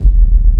41 BASS02 -R.wav